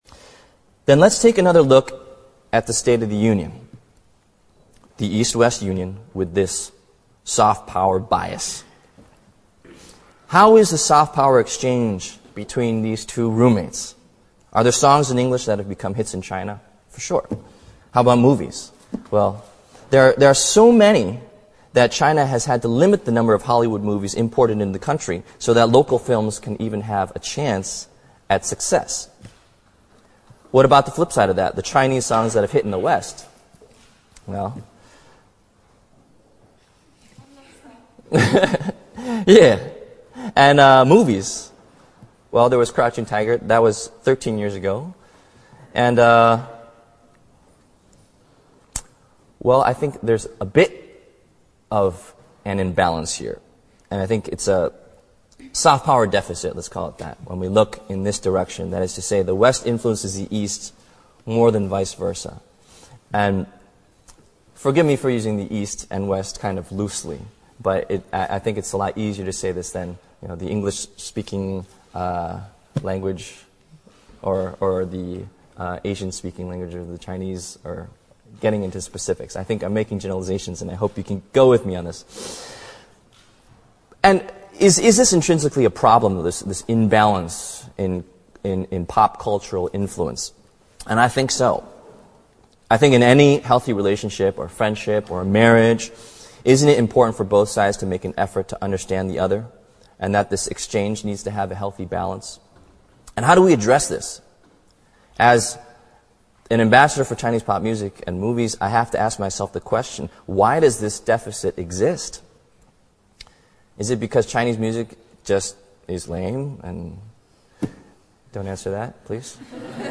王力宏牛津大学演讲 第8期 听力文件下载—在线英语听力室
在线英语听力室王力宏牛津大学演讲 第8期的听力文件下载,哈佛牛津名人名校演讲包含中英字幕音频MP3文件，里面的英语演讲，发音地道，慷慨激昂，名人的效应就是激励他人努力取得成功。